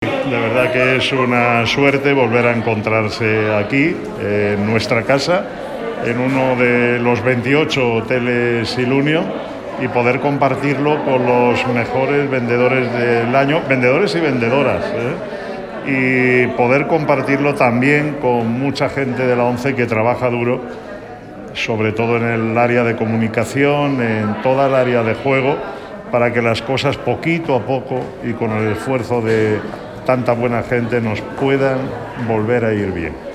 aseguraba formato MP3 audio(0,64 MB) a su llegada al evento el presidente del Grupo Social ONCE, Miguel Carballeda